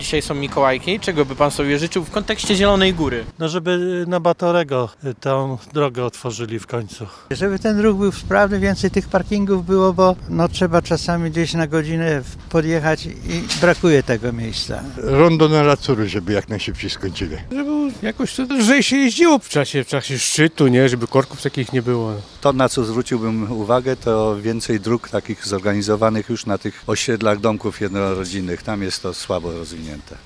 Mikołajki [SONDA]
My natomiast zapytaliśmy mieszkańców o ich życzenia: